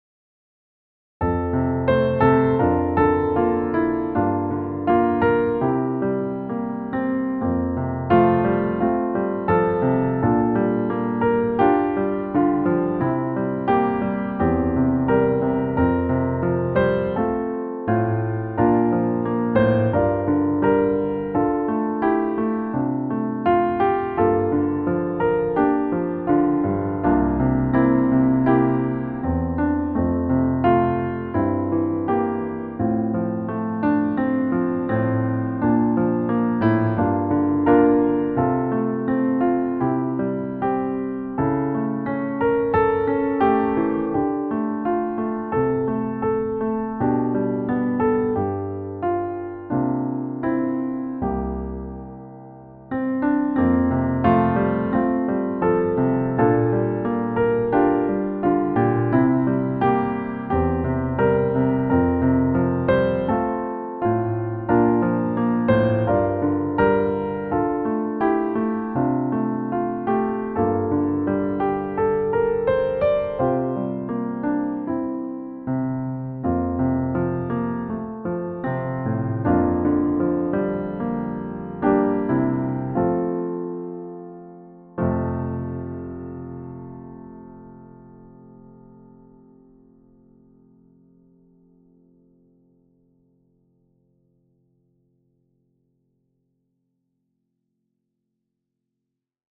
Vocal Solo Medium Voice/Low Voice